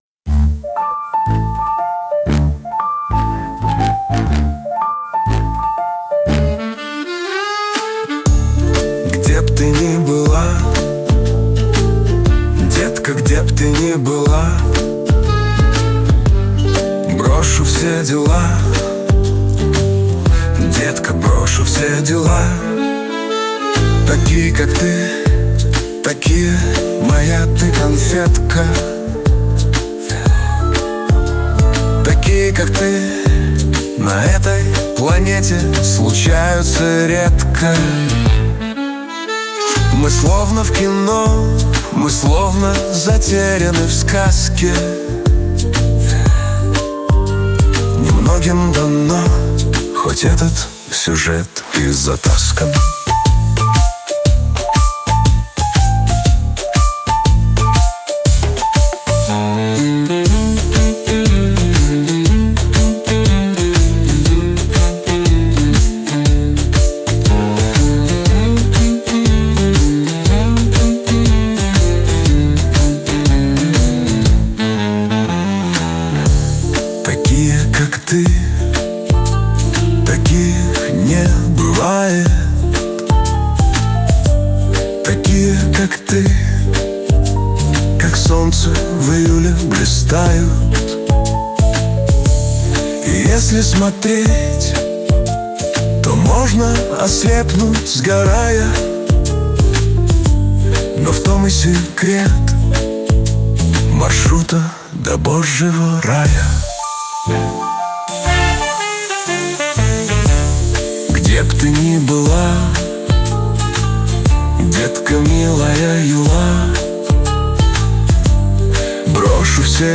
• Исполняет: Suno
• Аранжировка: Suno